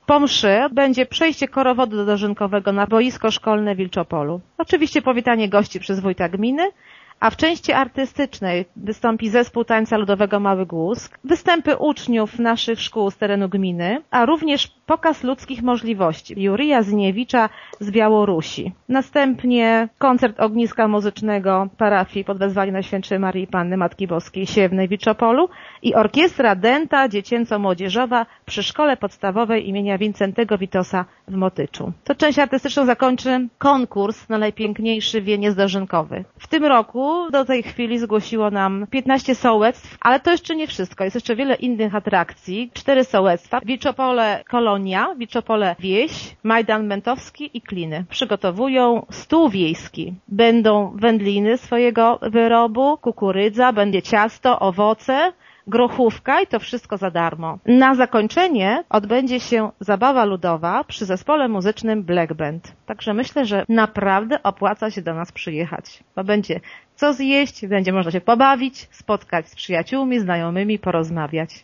Do udziału we wspólnym świętowaniu zapraszała Urszula Paździor, zastępca wójta Gminy Głusk.